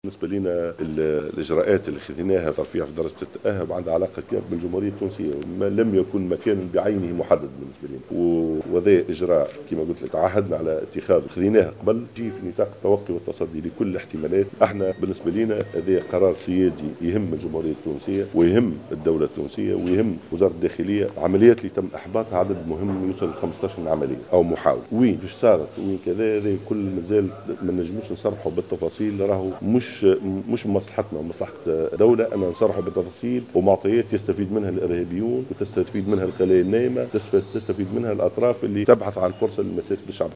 وأوضح في تصريح اعلامي على هامش لقاء في العاصمة تونس مع قيادات حزبية حول قانون الانتخابات البلدية، ان هذا الإجراء سيطبق بكامل أنحاء الجمهورية التونسية ولا يقتصر على مكان بعينه، بحسب تأكيده.